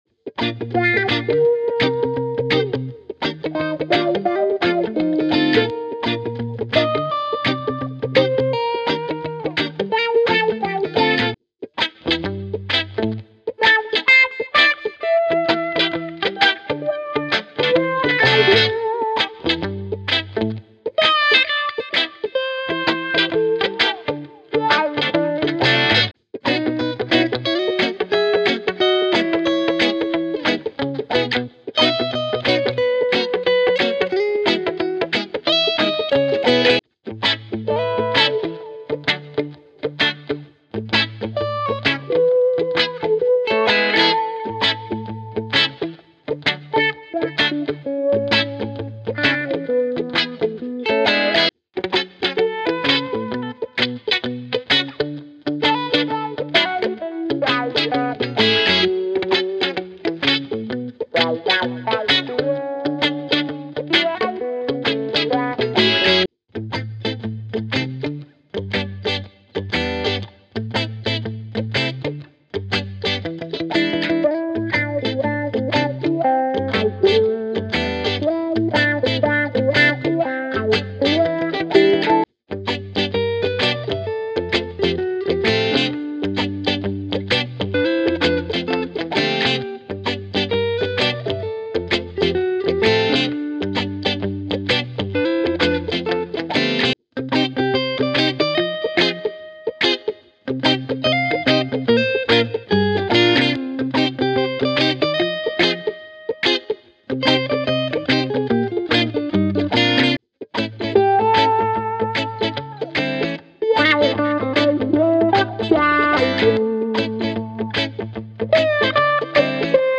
Elevate your tracks with the authentic sound of reggae guitar!
10 Guitar Ensemble Loops: Rich, layered guitar sections that provide a full, immersive sound, perfect for building the foundation of your track.
10 Rhythm Guitar Loops: Solid, groove-centric rhythms to drive your tracks forward with that classic reggae feel.
20 Muted Rhythm Guitar Loops: Tight, percussive strumming patterns that add subtle movement and texture to your music.
10 Lead Guitar Loops: Expressive lead lines that cut through the mix, adding melodic interest and character.
10 Wah Wah Guitar Rhythm Loops: Funky, dynamic wah-wah rhythms that bring a playful, groovy element to your productions.
Tempo Range: Loops range from 120 to 180 BPM, providing versatility for various reggae sub-genres and creative projects.
High-Quality Sound: Professionally recorded and produced to ensure pristine audio quality.
Reggae-Guitars-Vol-1.mp3